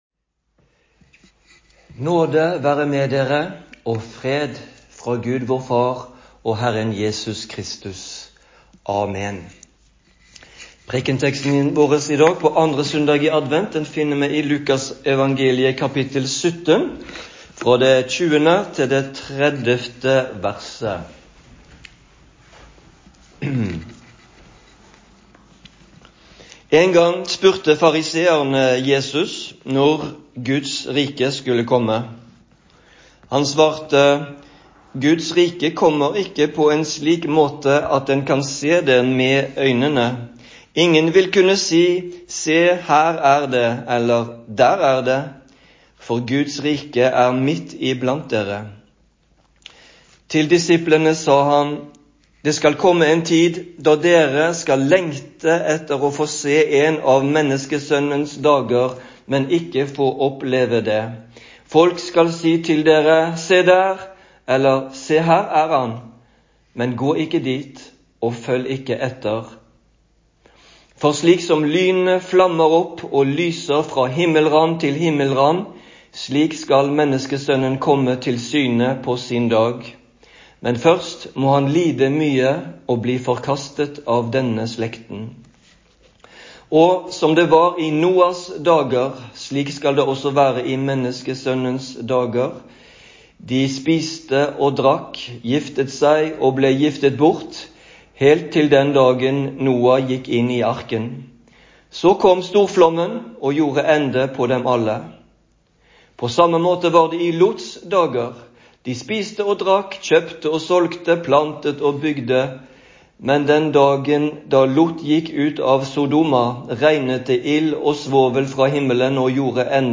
Preken på 2. søndag i advent